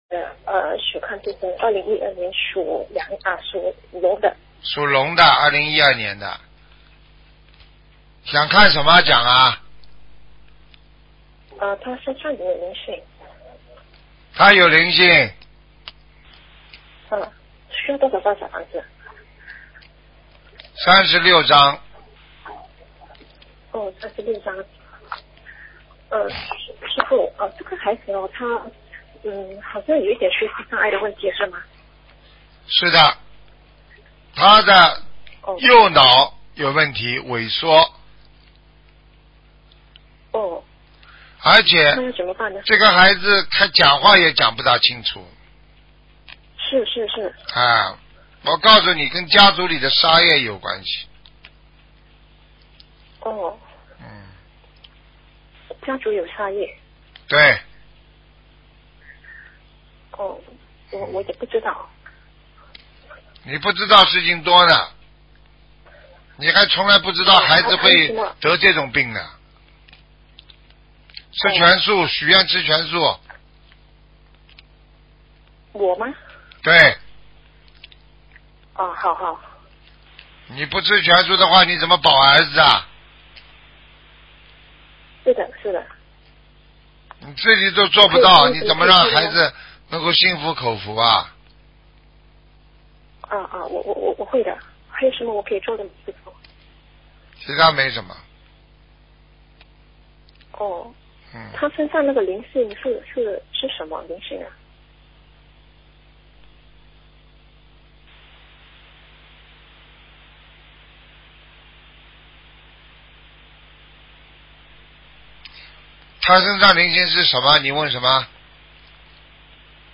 目录：2016年剪辑电台节目录音_集锦